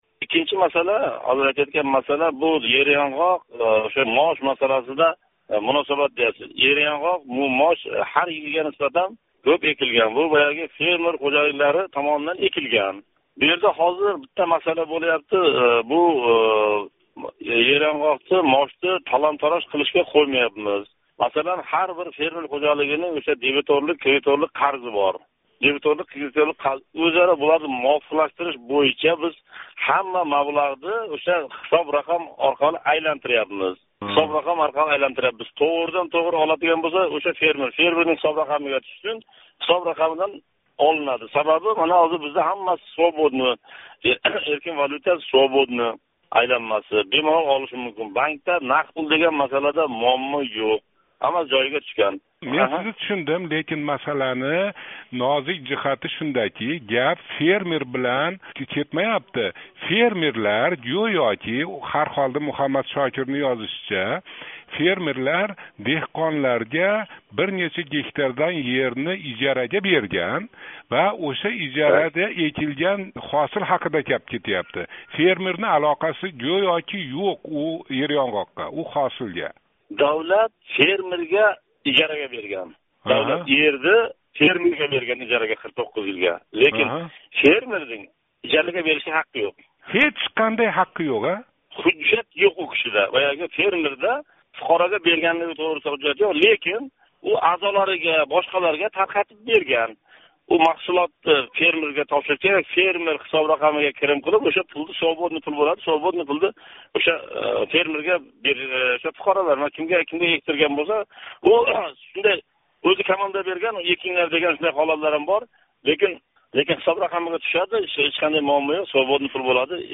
Сурхондарё вилоят ҳокимининг биринчи ўринбосари Тўра Боболов билан суҳбат